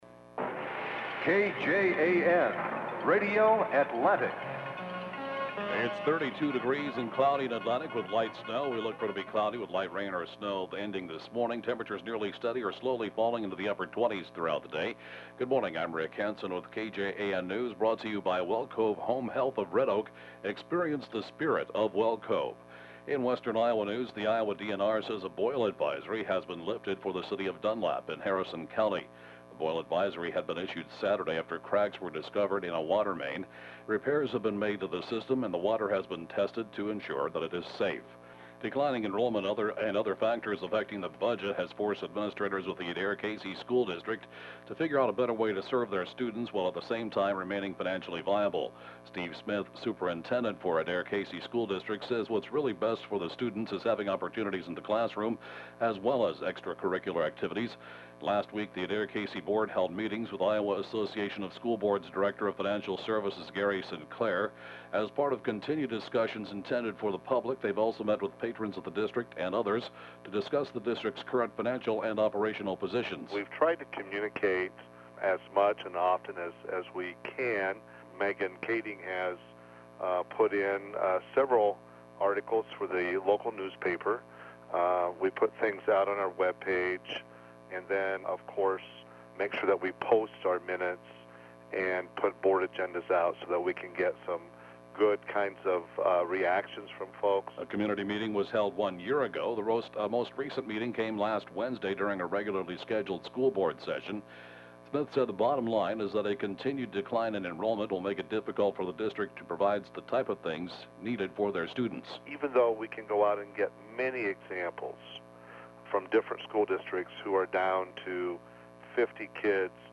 (Podcast) 8-a.m. KJAN News, 11/26/2014